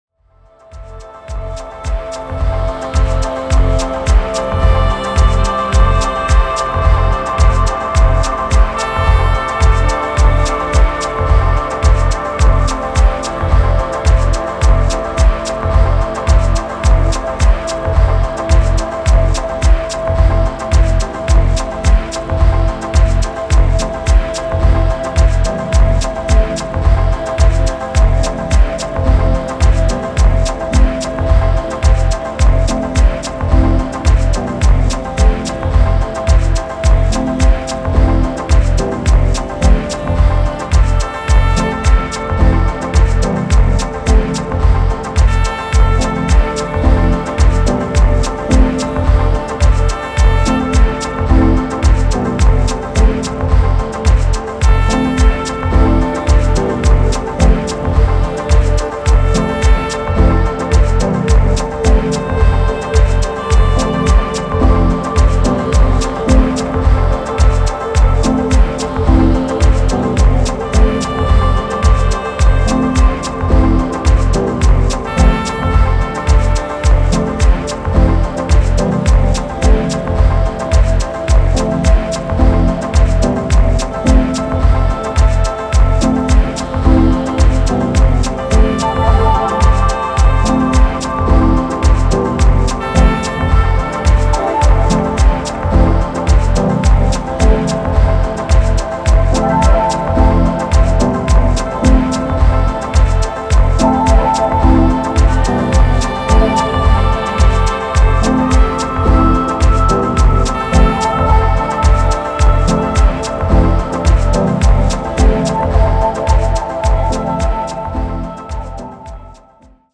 スピリチュアルなディープハウス